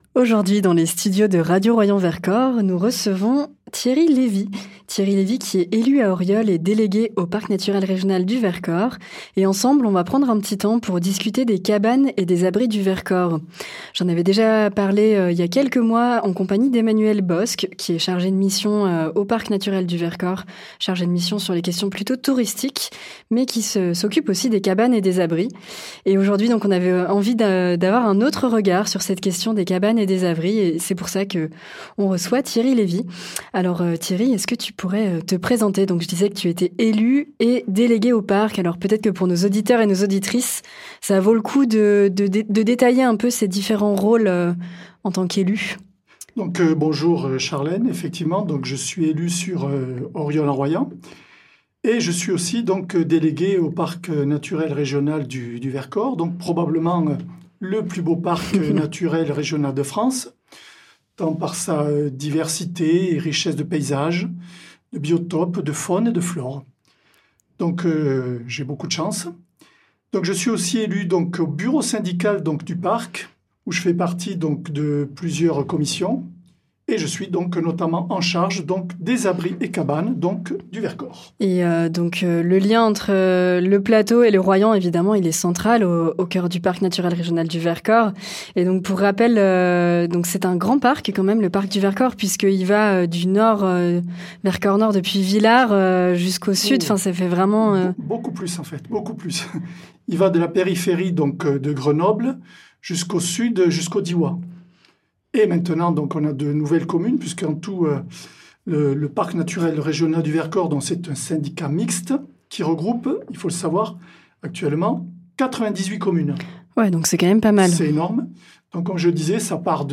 Thierry Levy est élu à Oriol-en-Royans et délégué au Parc Naturel Régional du Vercors. A notre micro, il revient sur le plan de rénovation des cabanes et abris du Vercors : un projet qui a débuté en 2019 et qui a déjà permis de réaliser des travaux sur neuf cabanes.